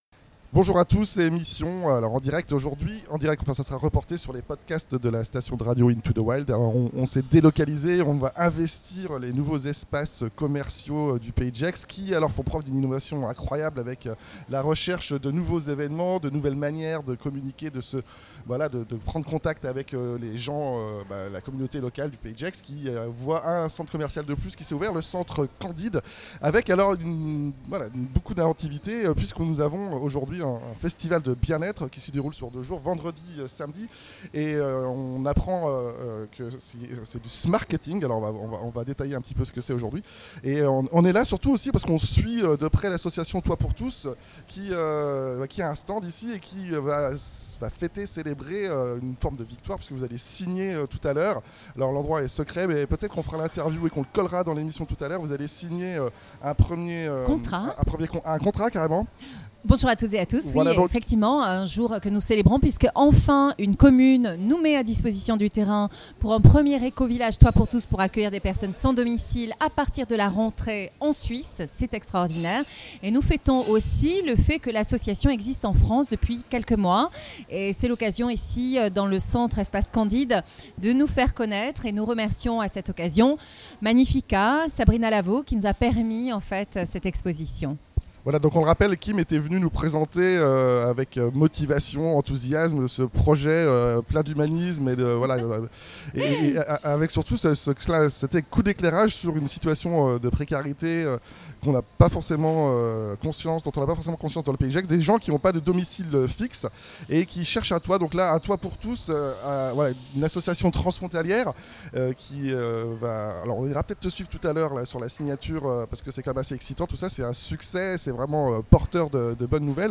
Plongée et discussions au cœur du festival du bien être organisé ce weekend au milieu des boutiques, interviews groupés des têtes pensantes de ces nouvelles approches du smartketing et feedback des intervenants, thérapeutes et développeurs personnels sans frontières. On apprend enfin la verité sur les rebouteux en fin d'emission!